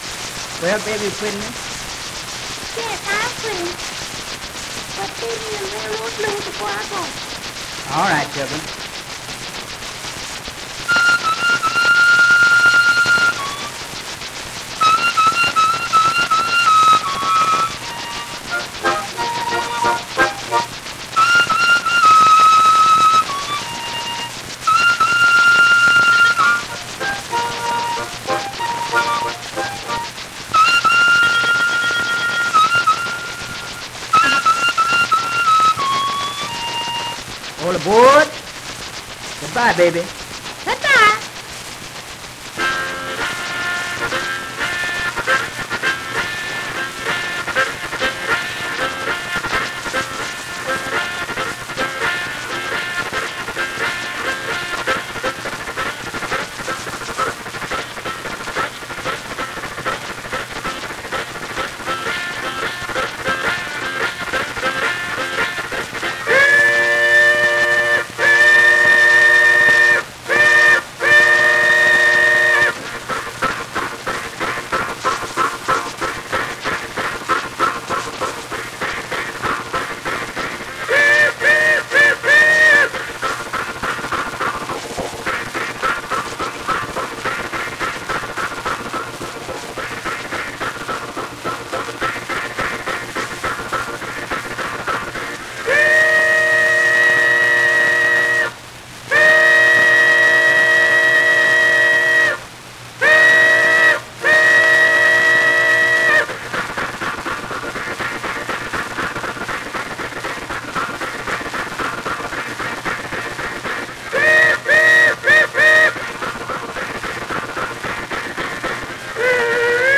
unaccompanied harmonica solos
is an archetypal (and quite excellent) train piece